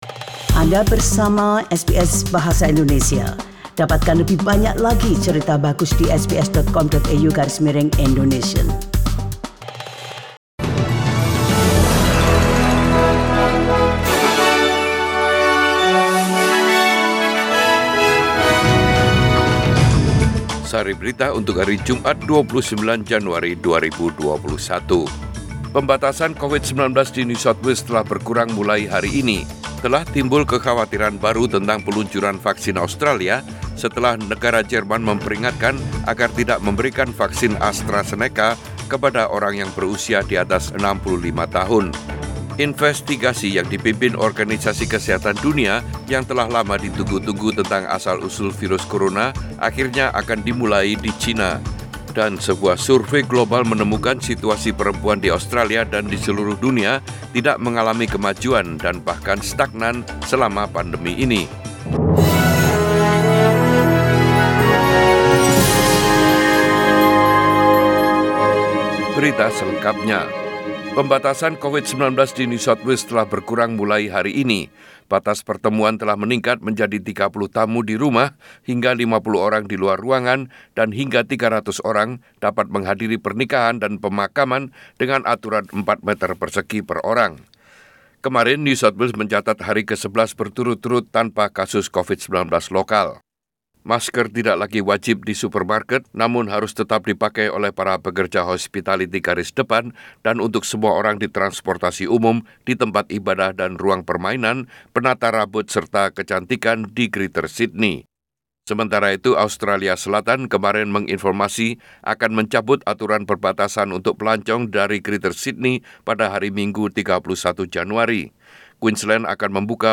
SBS Radio News in Bahasa Indonesia - 29 Janury 2021